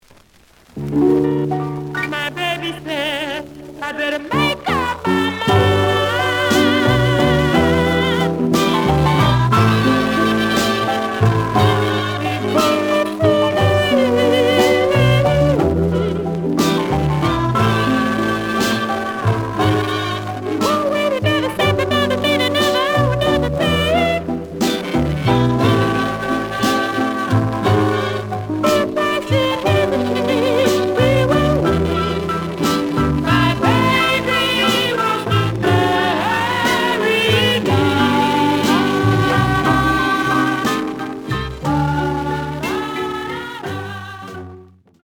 The audio sample is recorded from the actual item.
●Genre: Rhythm And Blues / Rock 'n' Roll
Sound distortion on side B caused by pressing.)